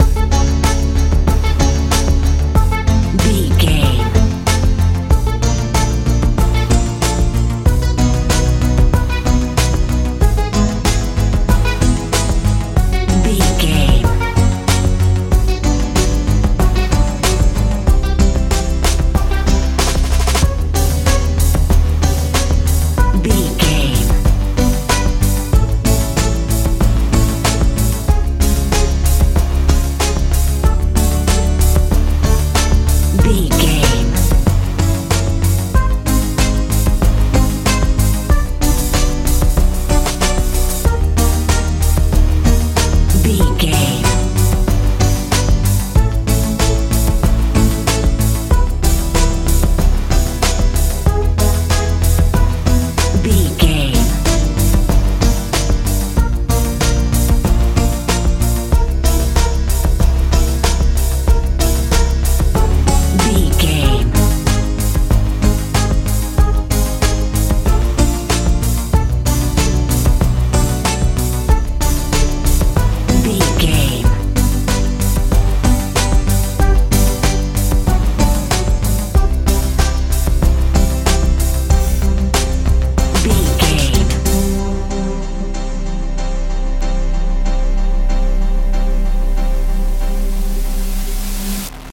kpop feel
Ionian/Major
bouncy
dreamy
synthesiser
bass guitar
drums
80s
strange